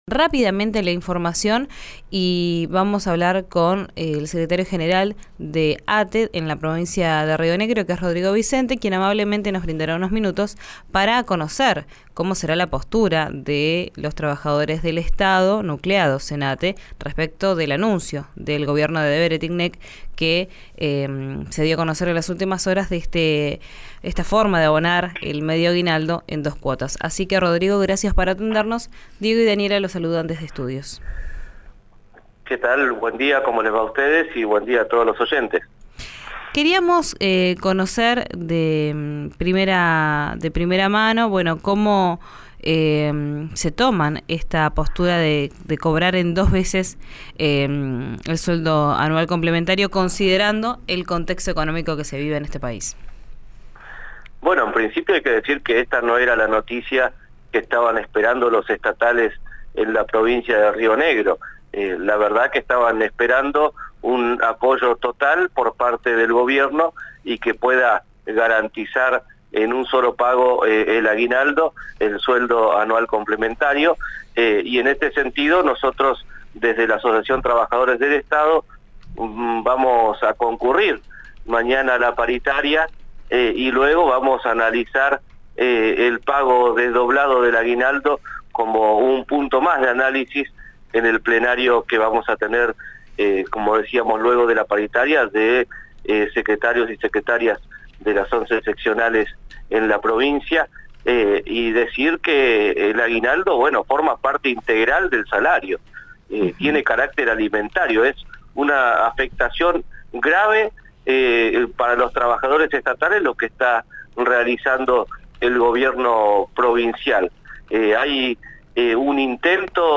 ante los micrófonos de RÍO NEGRO RADIO